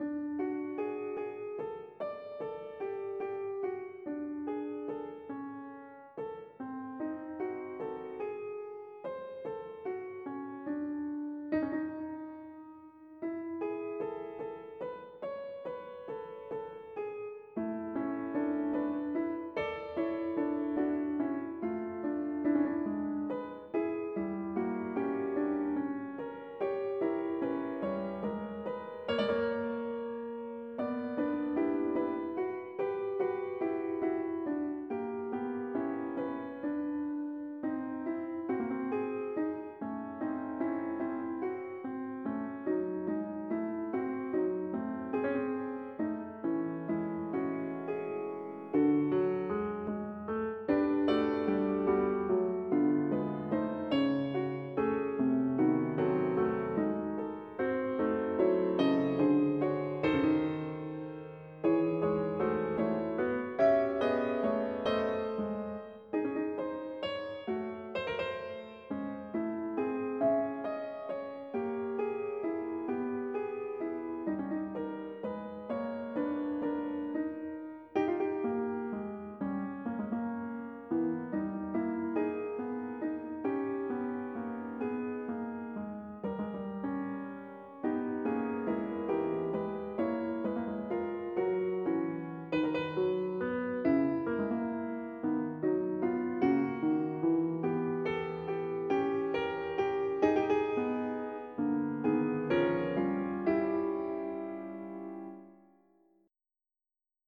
Exposition of Fugue in D major - WIP